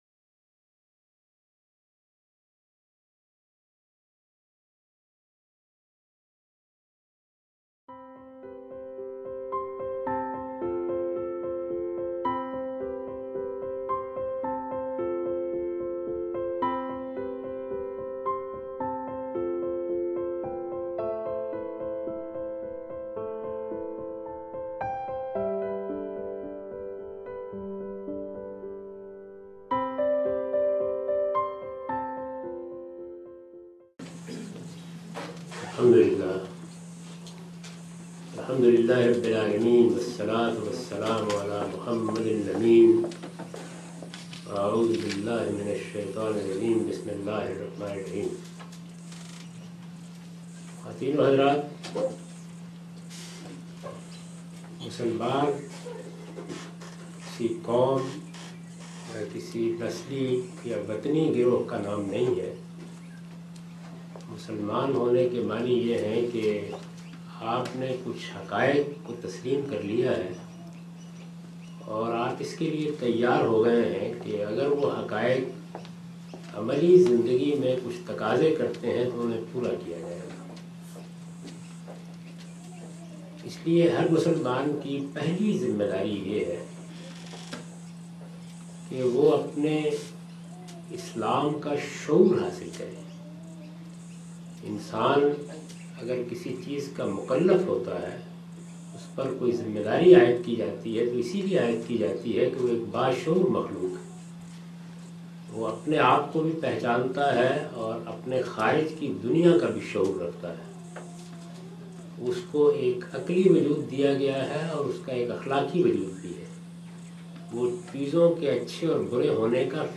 This series of lectures was recorded in Australia in January 2014.